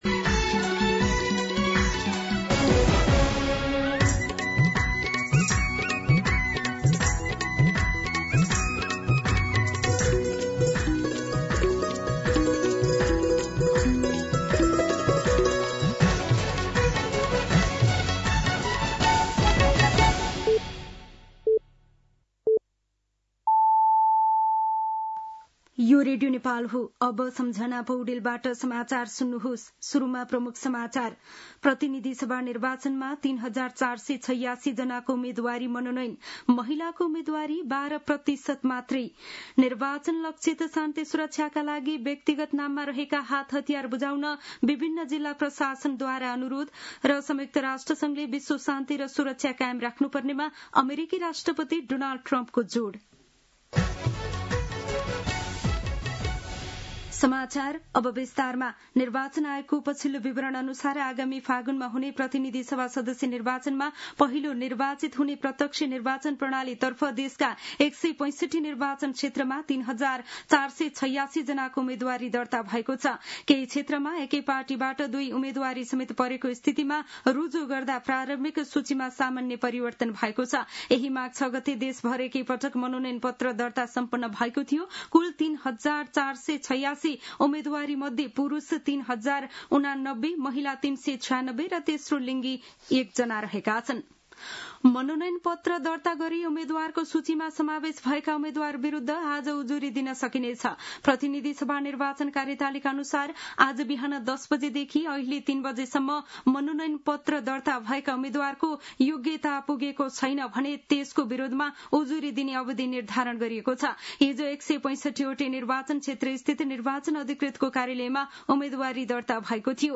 दिउँसो ३ बजेको नेपाली समाचार : ७ माघ , २०८२
3-pm-Nepali-News-5.mp3